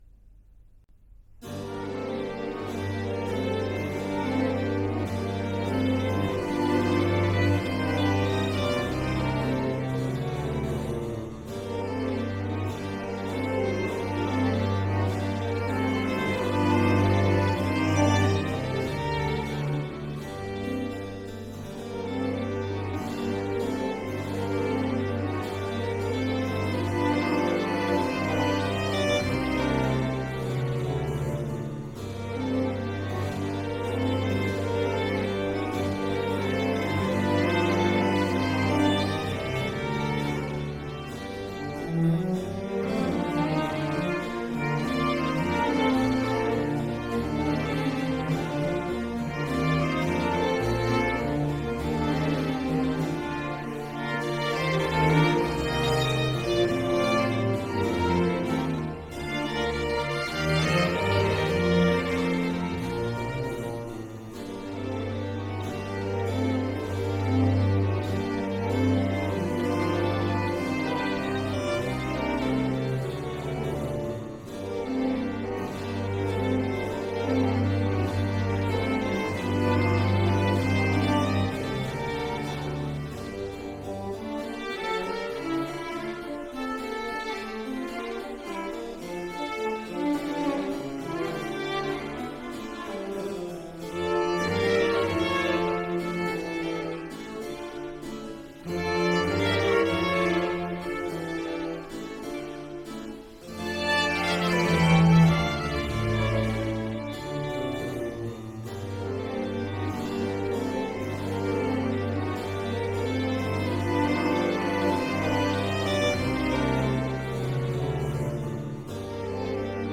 three violins, viola and two cellos